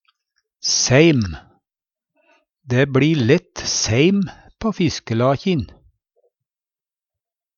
seim - Numedalsmål (en-US)